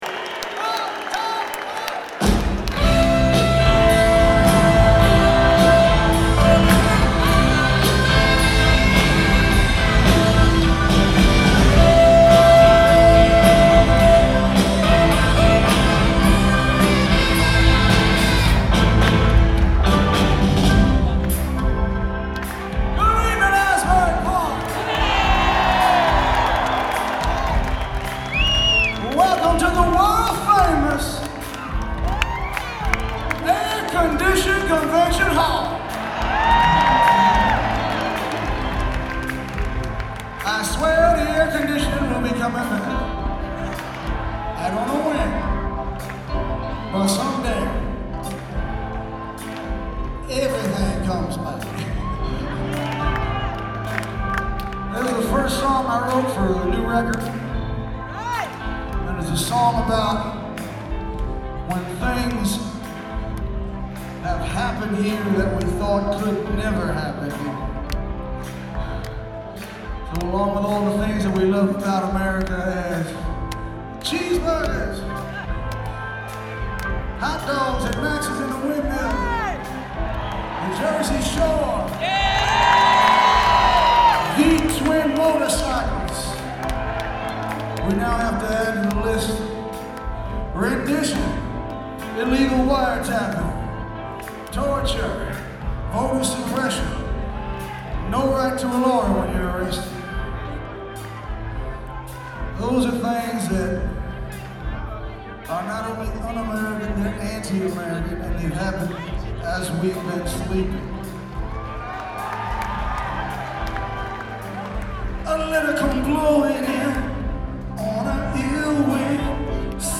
un bel concerto
presso la Convention Hall, Asbury Park, New Jersey